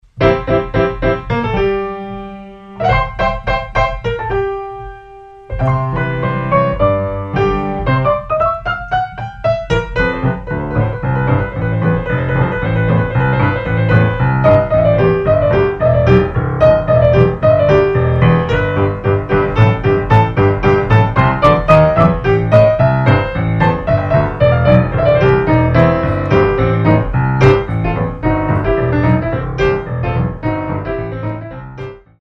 Style: Barrelhouse Piano